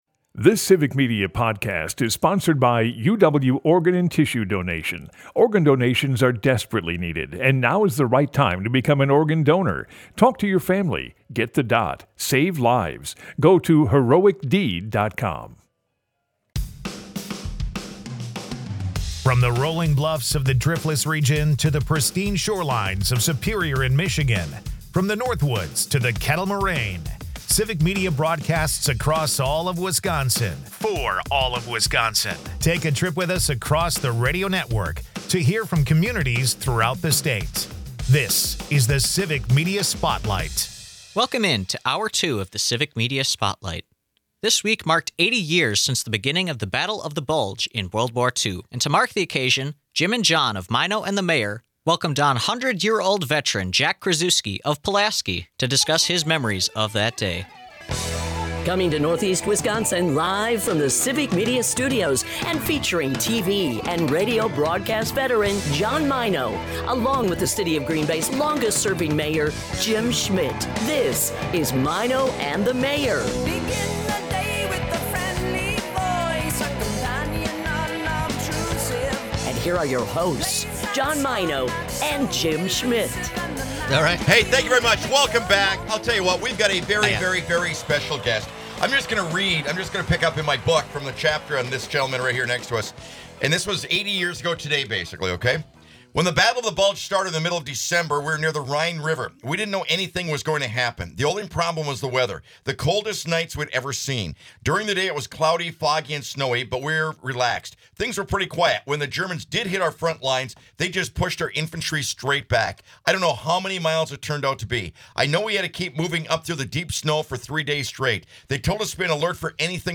They take calls from the listeners and read texts, as well who very happy to share their thoughts with the gang about Tuesday night.